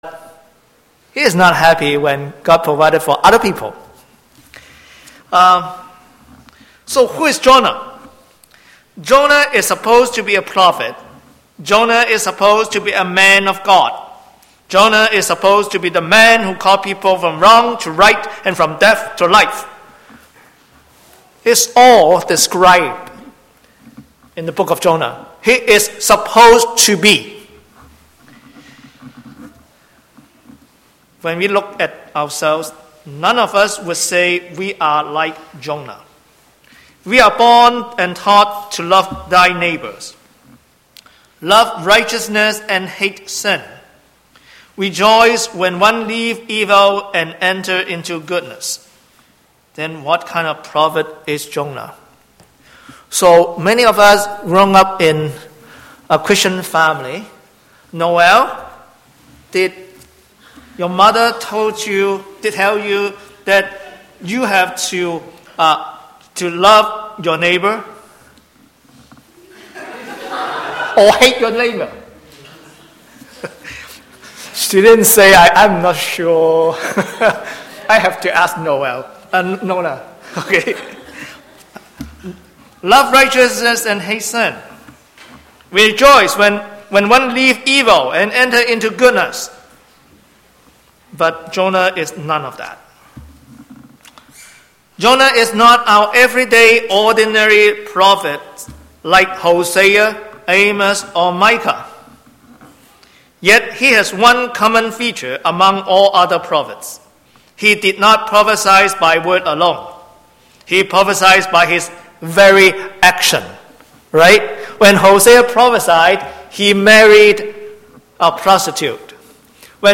Download Download Reference Jonah 4:1-11 From this series Current Sermon What Becomes of Jonah?